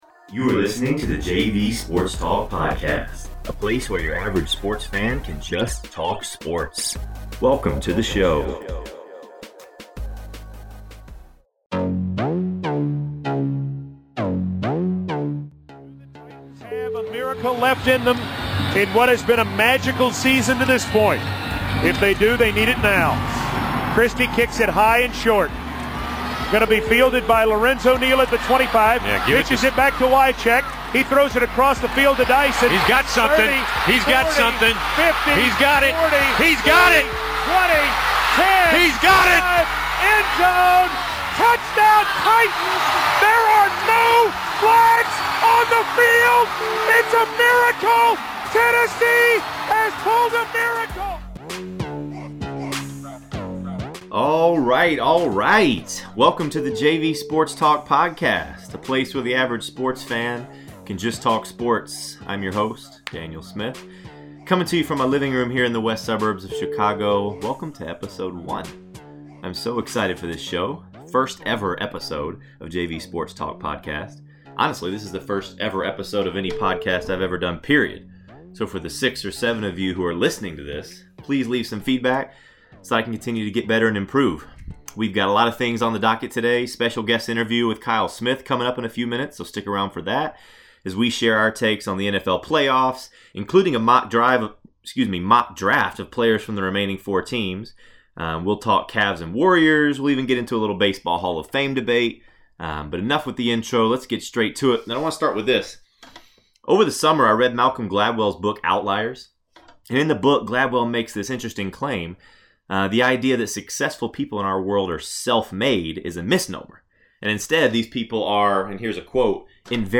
Join me as I interview a guest each week to talk sports, life, faith, sports, current events, movies, and more sports!